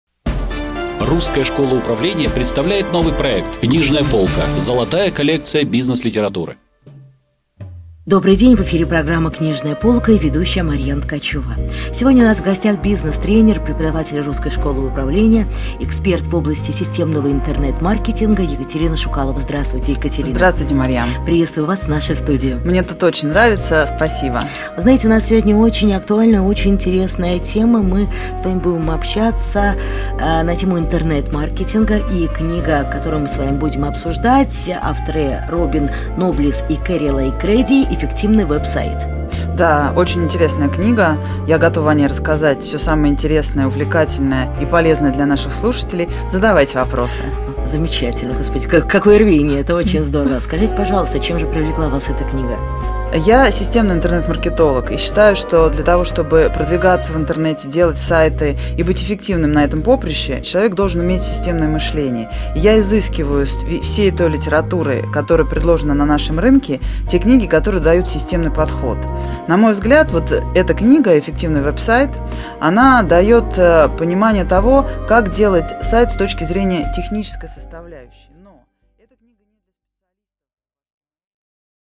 Аудиокнига Обзор книги Р. Ноблеса и К. Греди «Эффективный Web-сайт» | Библиотека аудиокниг